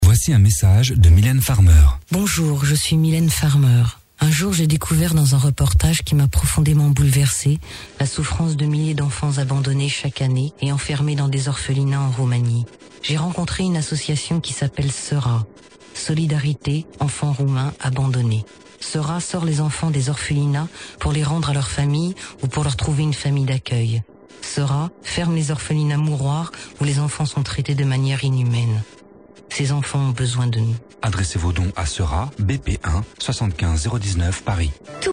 C'est une première, on peut entendre Mylène Farmer parler de l'association SERA (Solidarité Enfants Roumains Abandonnés) sur les ondes radios. Avec un ton assez grave elle nous informe de l'existence de cette organisme et son utilité envers les enfants roumains.
Un extrait audio de l'annonce est disponible